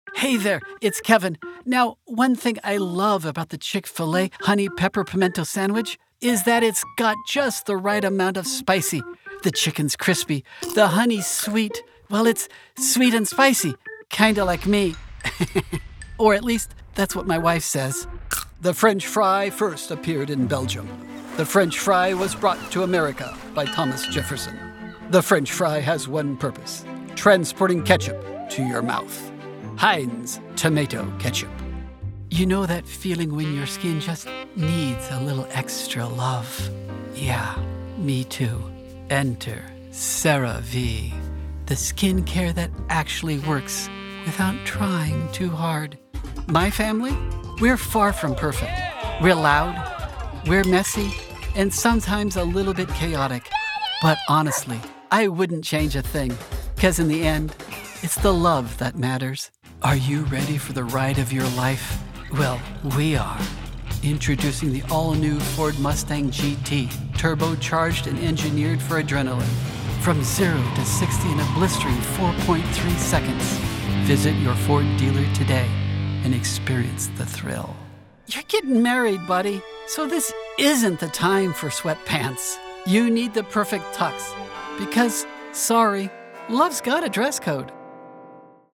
Voice Over Artist
A Unique and Unexpected Comedic Voice